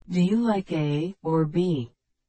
ちなみに、or を使った疑問文では、最後だけイントネーションが下がります。
Do you like A or B ? では A↑ or B↓ となり、